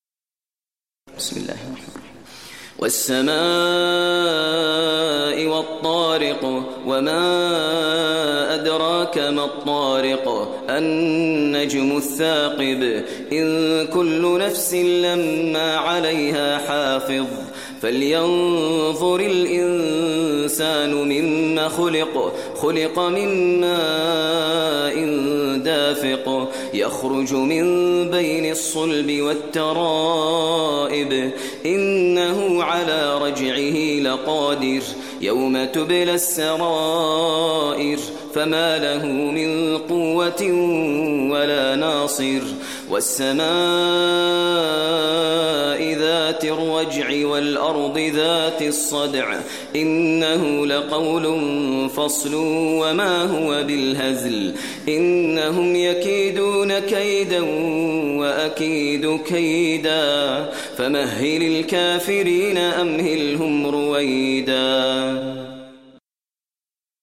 Surah Tariq Recitation by Maher al Mueaqly
Surah Tariq, listen online mp3 tilawat / recitation in Arabic recited by Imam e Kaaba Sheikh Maher al Mueaqly.